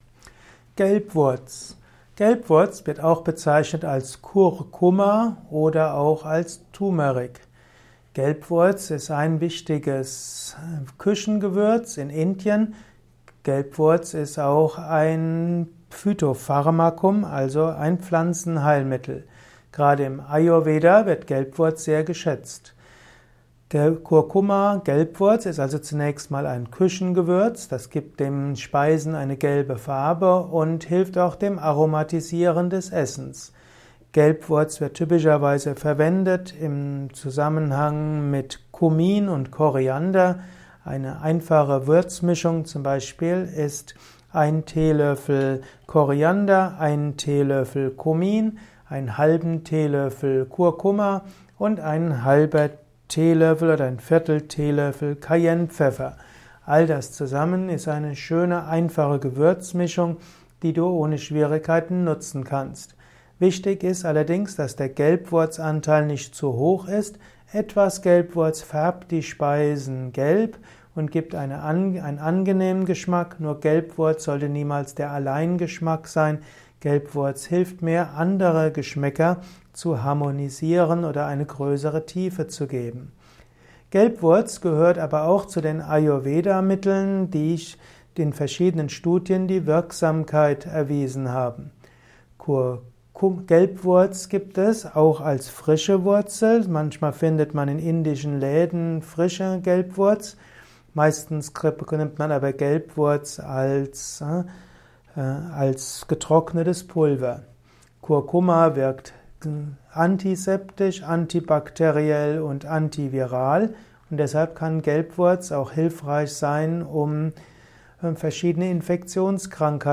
Ein Kurzvortrag über Gelbwurz